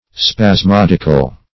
spasmodical - definition of spasmodical - synonyms, pronunciation, spelling from Free Dictionary
Search Result for " spasmodical" : The Collaborative International Dictionary of English v.0.48: Spasmodical \Spas*mod"ic*al\, a. Same as Spasmodic , a. -- Spas*mod"ic*al*ly , adv.